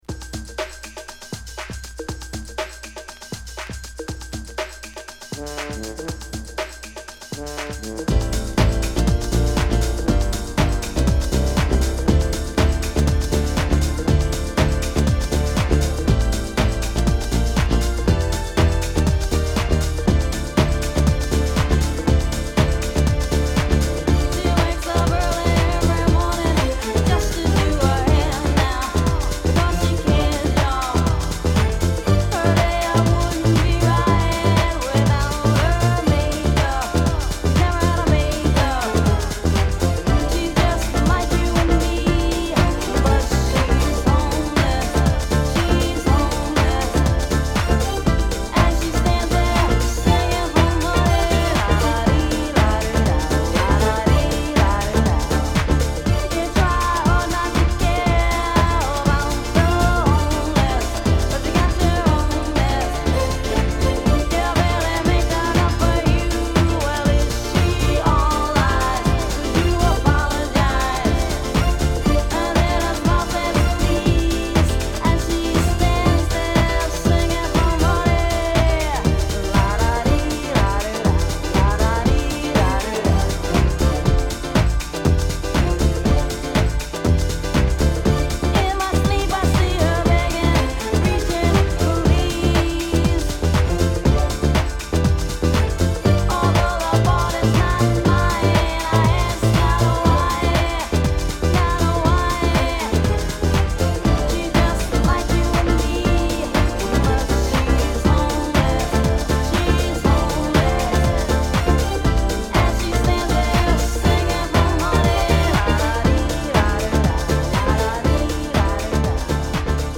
弾んだシンセメロに「ラダディ〜ラダダ」のコーラスが印象的な名曲！